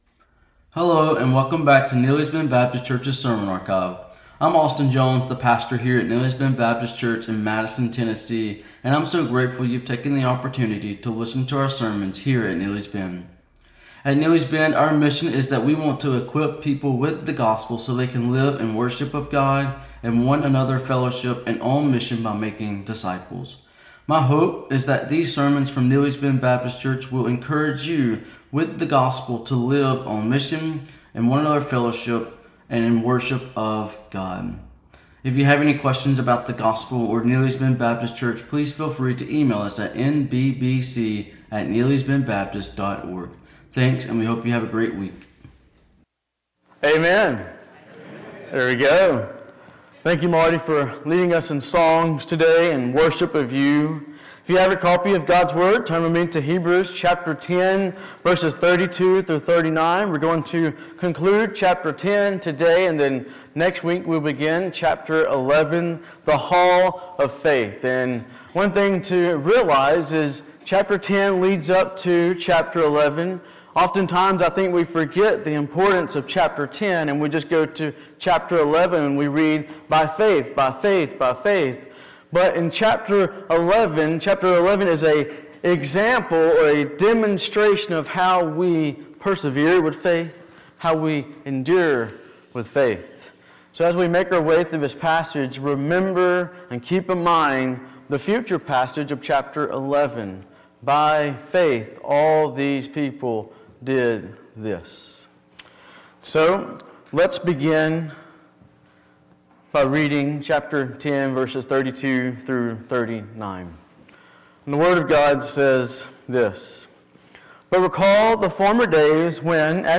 Sermons | Neely's Bend Baptist Church